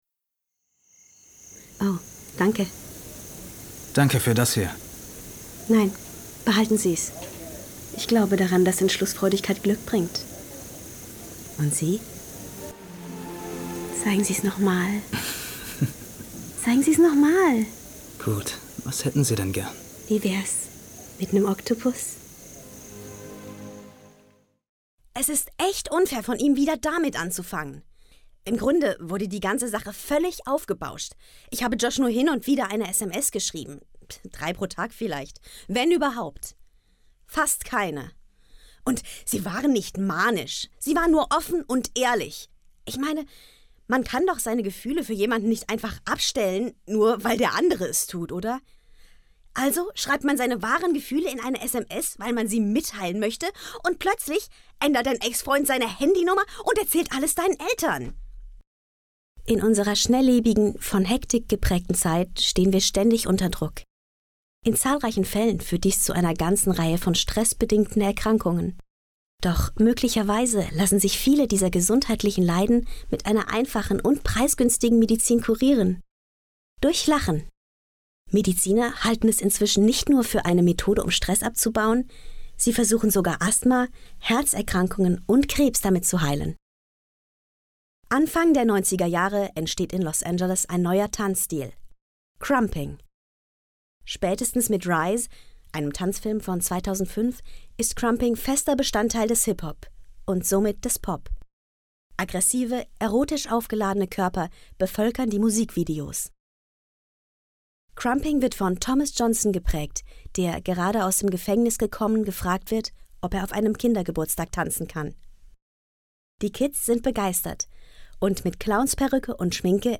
Sprecherin, Schauspielerin, junge Stimme, Kinderstimme, professionelle Gesangsausbildung Pop, Rock, Klassisch, Musical
Kein Dialekt
Sprechprobe: Sonstiges (Muttersprache):